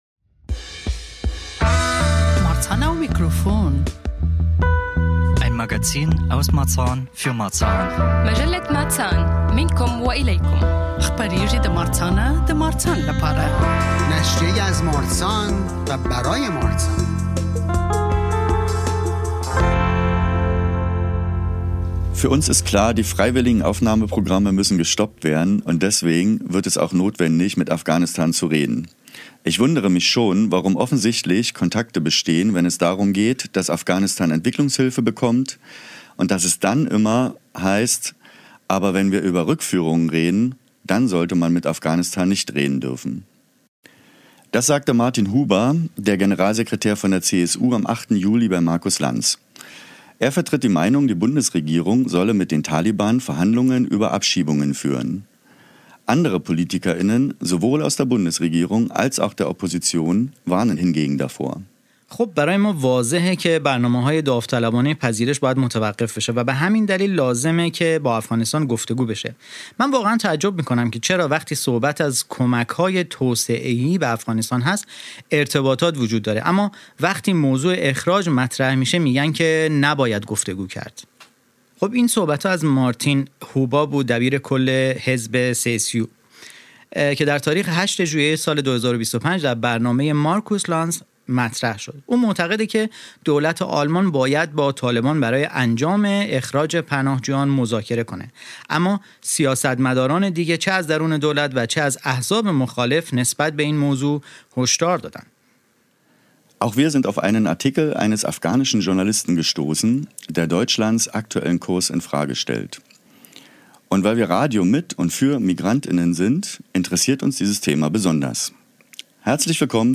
Studiogespräch: Abschiebungen nach Afghanistan ~ Marzahn am Mikro Podcast